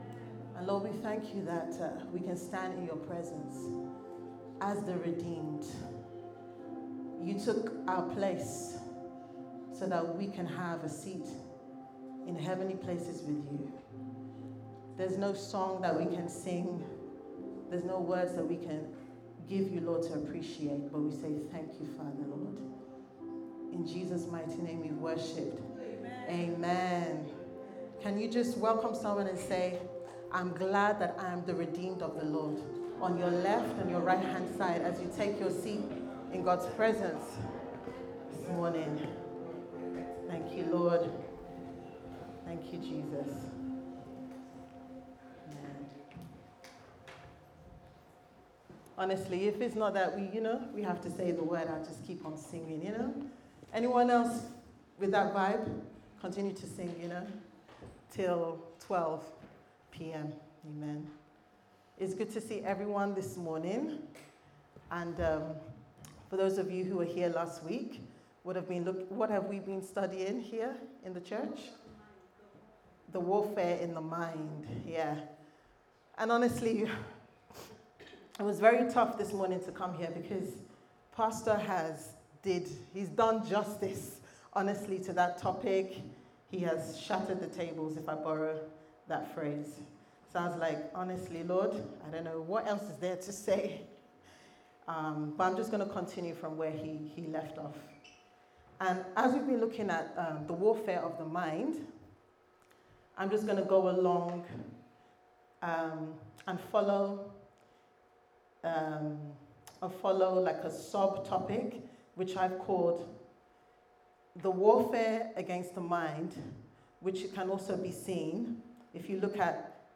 The Warfare In Your Mind Service Type: Sunday Service Sermon « What Are You Thinking About